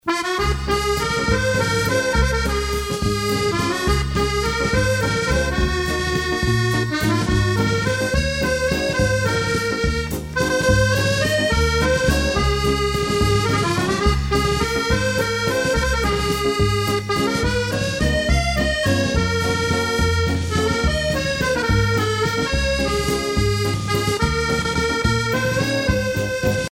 danse : valse musette
Pièce musicale éditée